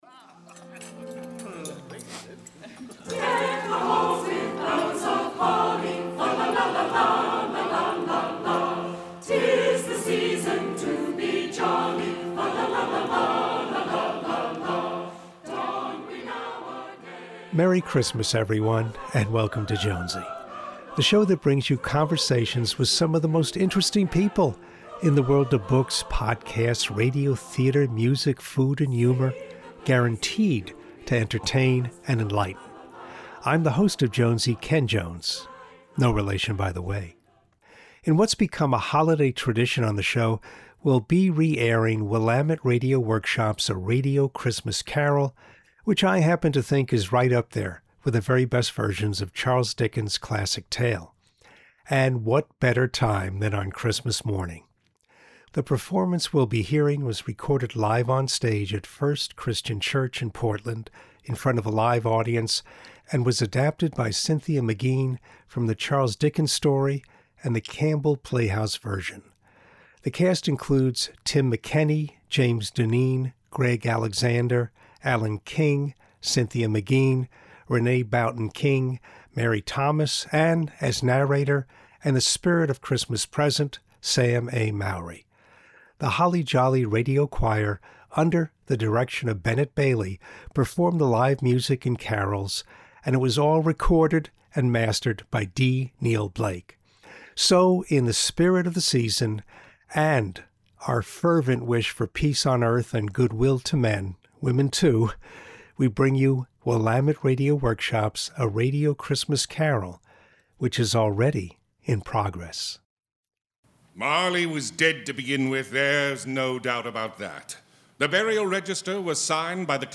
The performance we’ll be hearing was recorded live on-stage at First Christian Church in Portland in front of a live audience.
Radio Theatre